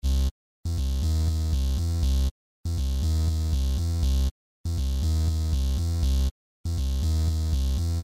描述：电子合成器循环
Tag: 环境 电子 合成器